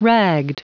Prononciation du mot ragged en anglais (fichier audio)
Prononciation du mot : ragged